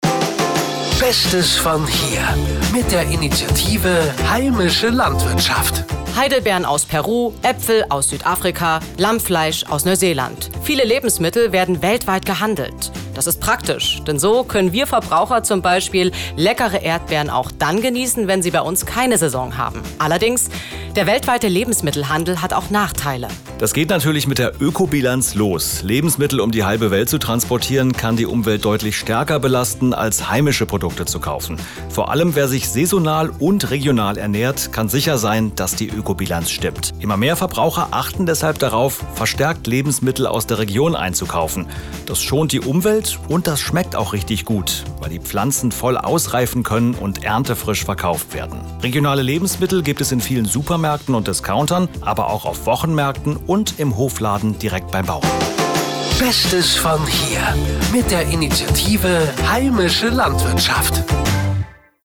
Ergänzt wurd die Aktion durch so genannte Infomercials, also spezielle Radiospots, die über die Vorteile regionaler Lebensmittel aufklären.
heimische_landwirtschaft_infomercial_1.mp3